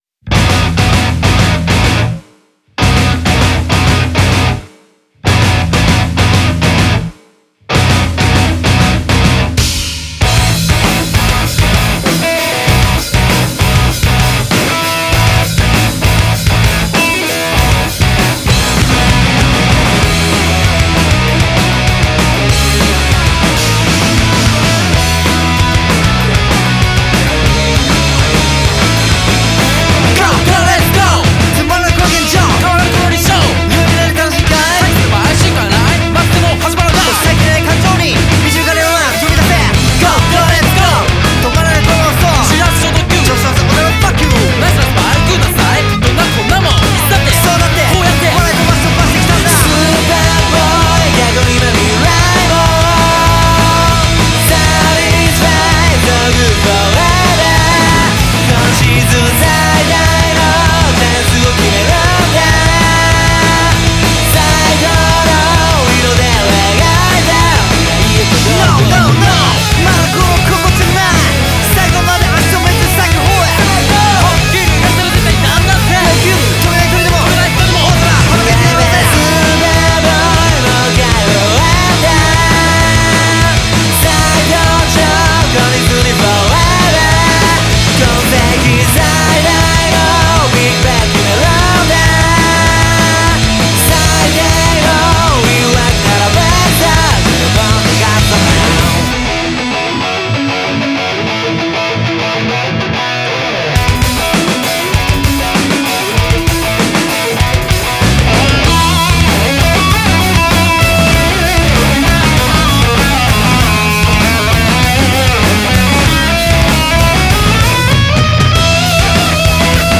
俺がサビ（SUPER BOY）を書いてます。